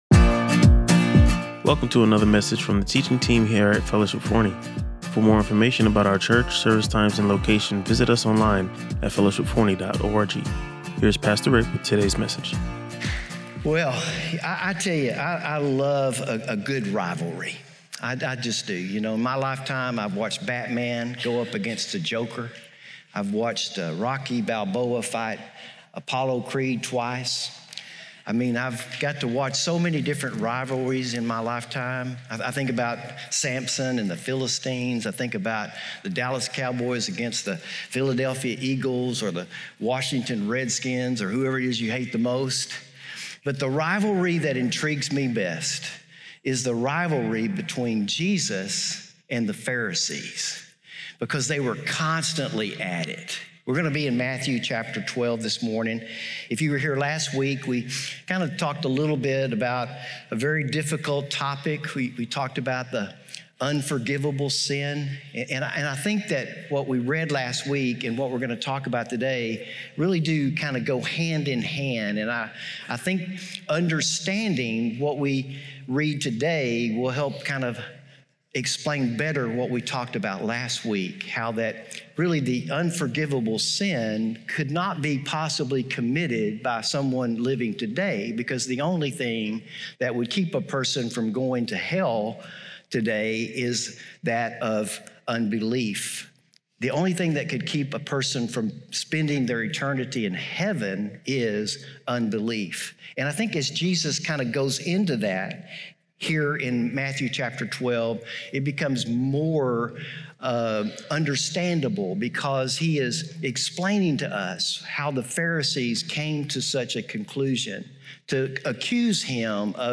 He emphasized how true transformation comes from within through the Holy Spirit, not through external changes. Using Galatians 5, he contrasted the works of the flesh with the fruit of the Spirit, reminding us that our inner nature inevitably manifests in our outward actions and words. The sermon concluded with a compelling discussion of signs, particularly focusing on Jesus’ resurrection as the ultimate validation of His claims.